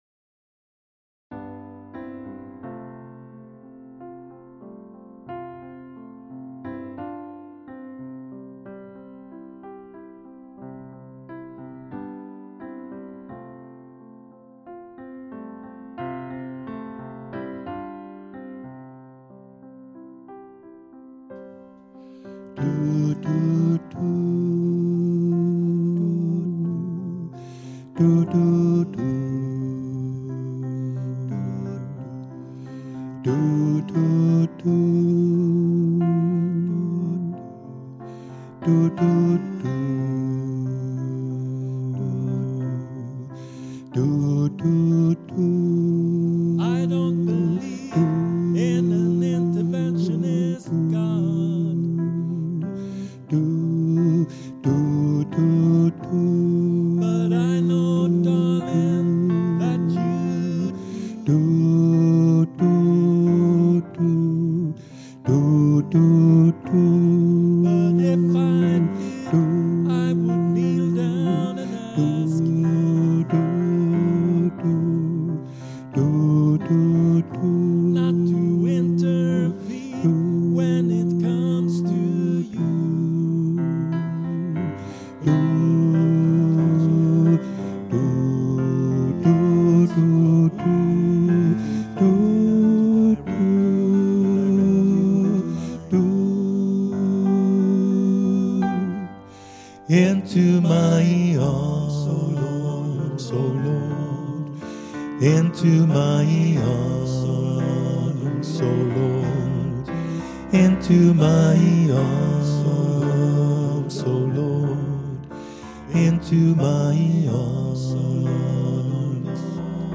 Into My Arms - sopran 2.mp3